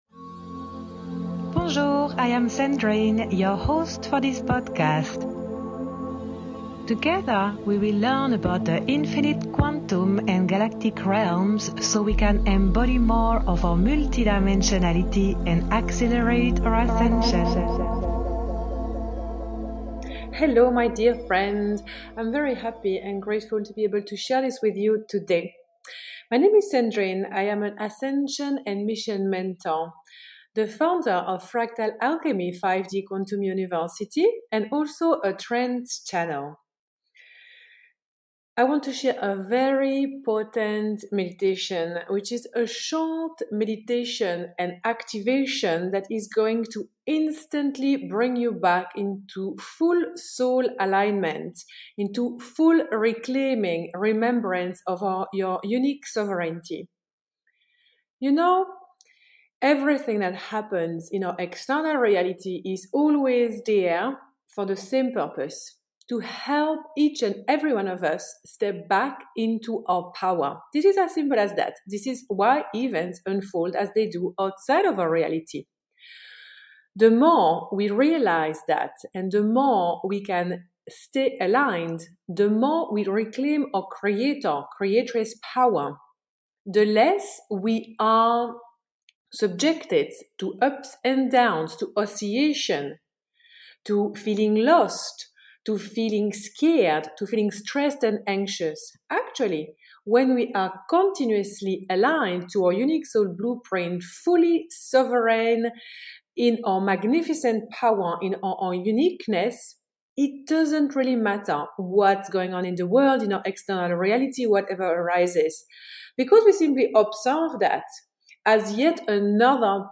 This episode is a recording of the live transmission that was facilitated on YouTube.